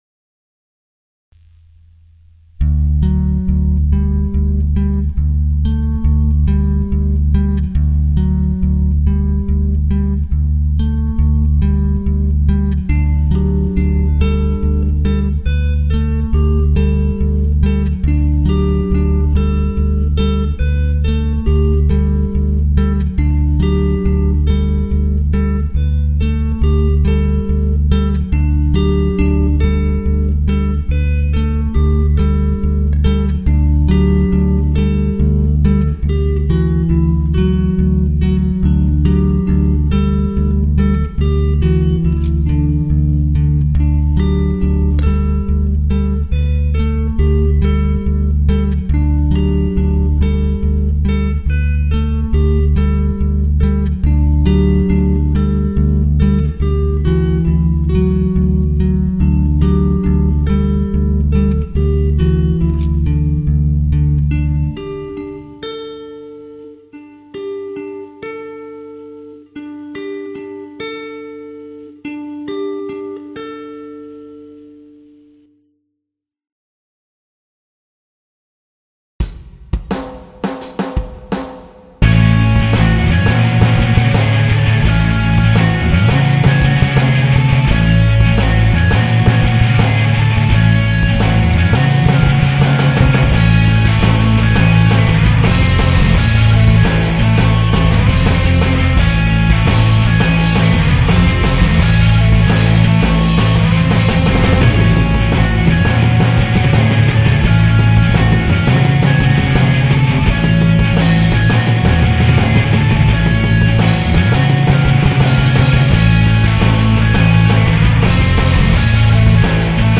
Inhoud van de muziek zijn de 4 basisemoties.